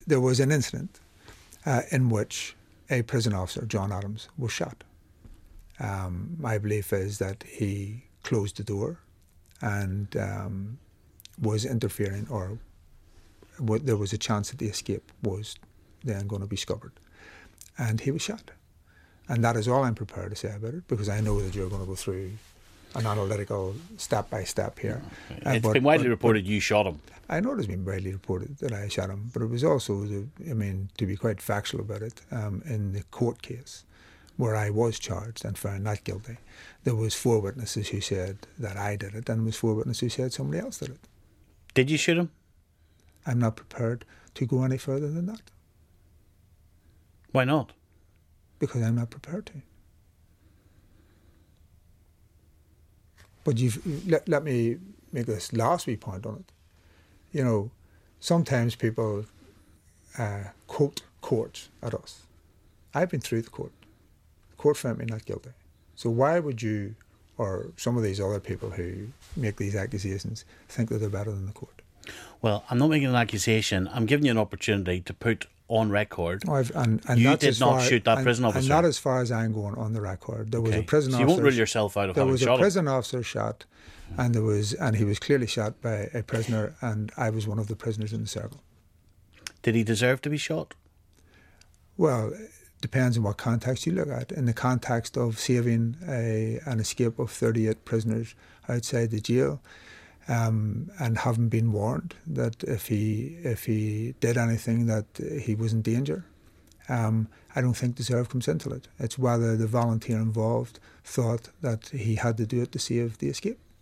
The full interview will be uploaded after broadcast.